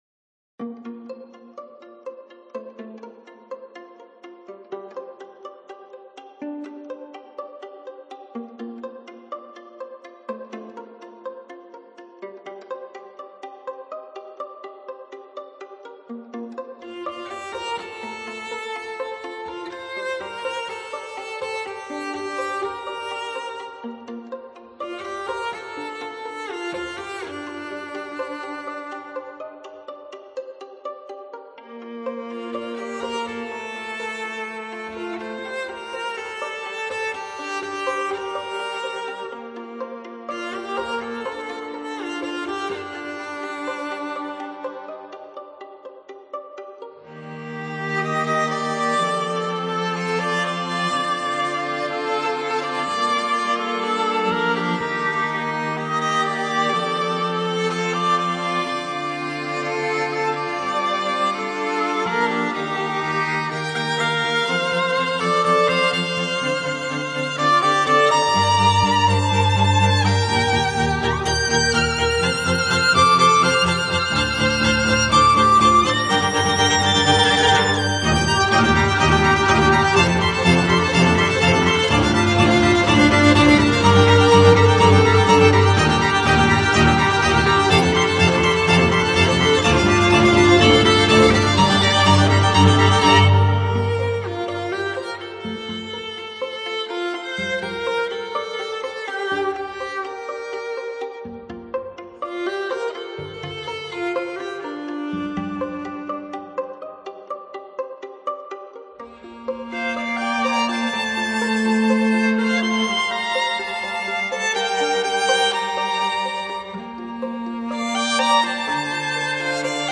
Жанр: Instrumental Symphonic Rock | Contemporary Classic